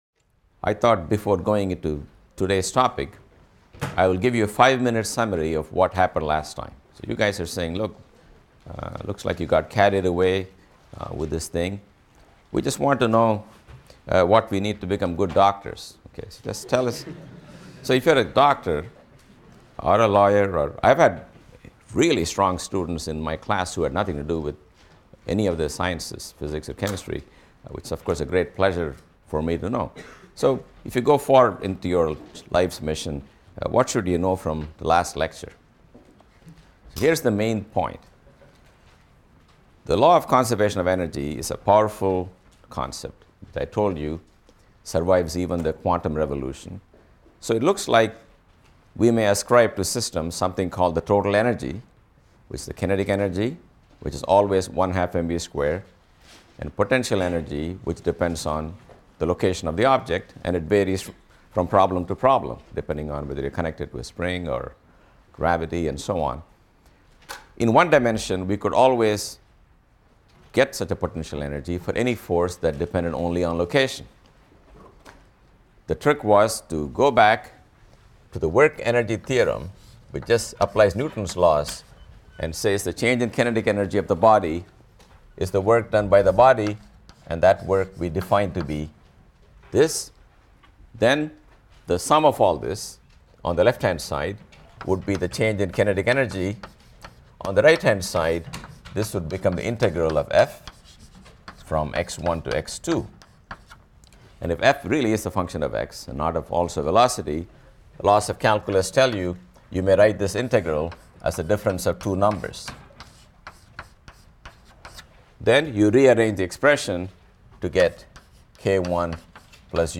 PHYS 200 - Lecture 7 - Kepler’s Laws | Open Yale Courses